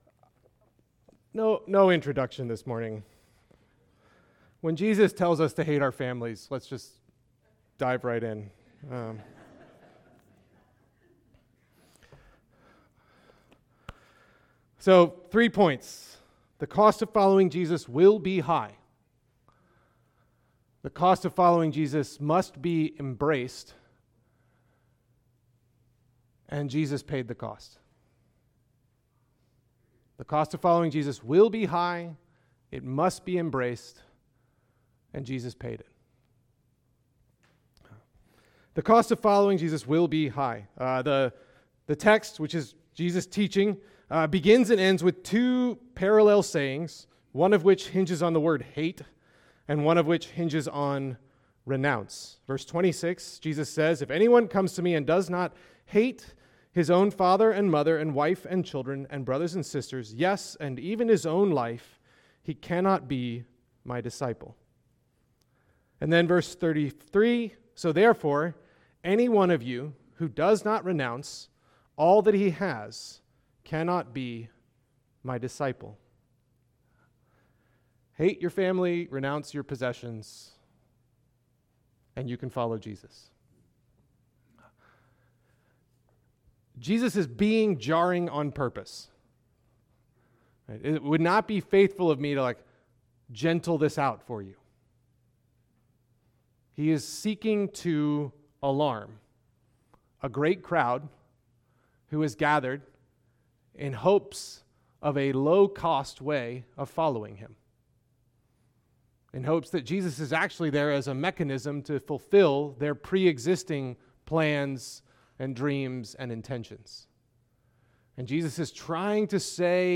Apr 20, 2026 | Teachings, Uncategorized